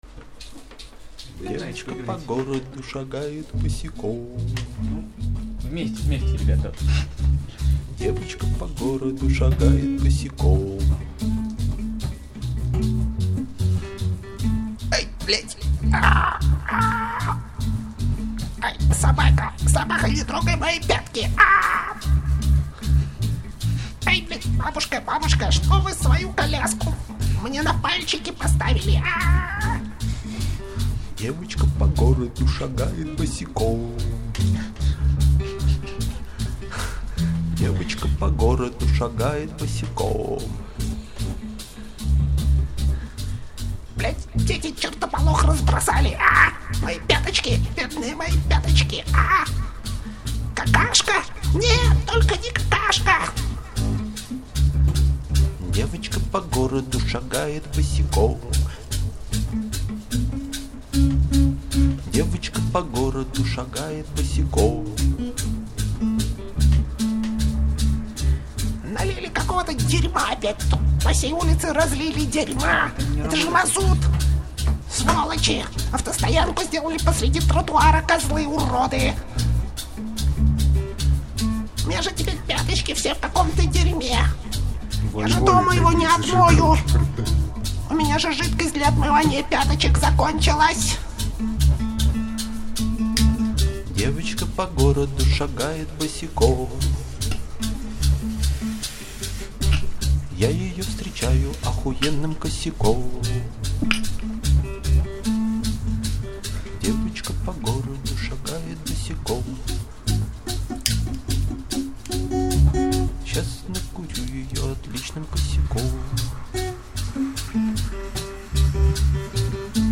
голос
бас-гитара
ударные, клавиши    Обложка